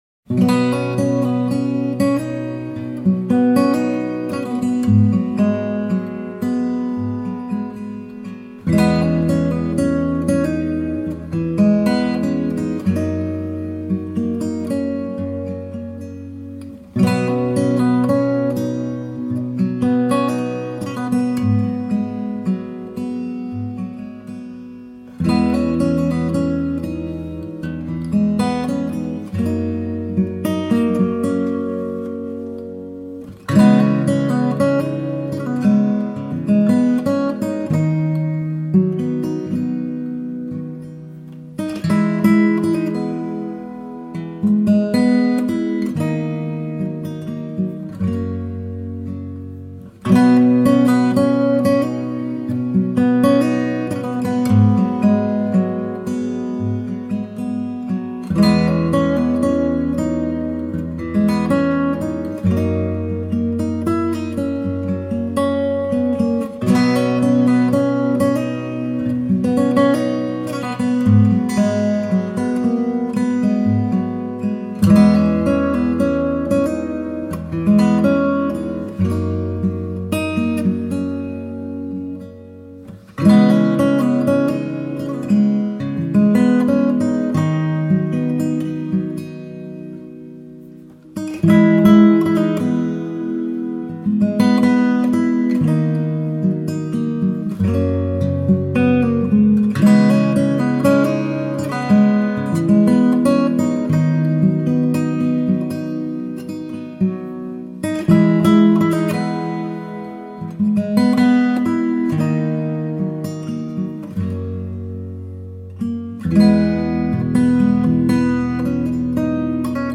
آرامش بخش , گیتار , موسیقی بی کلام